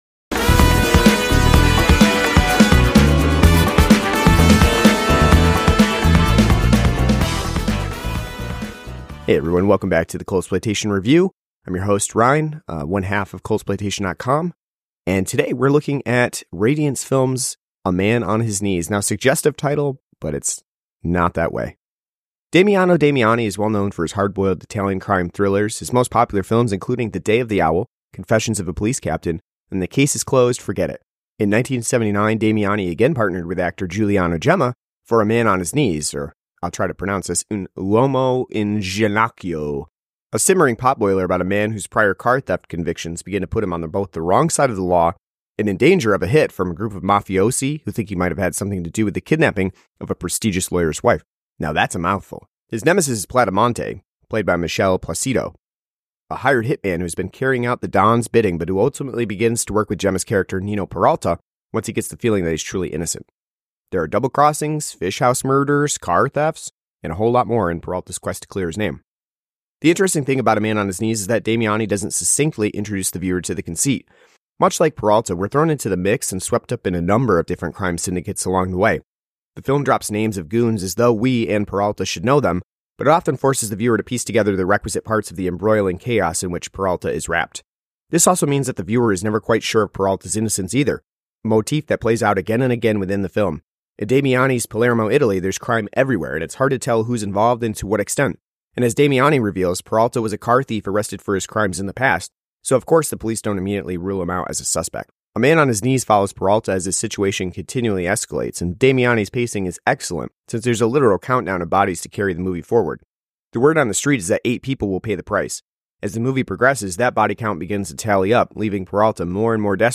An audio review of A Man on His Knees on Blu-ray from Radiance Films.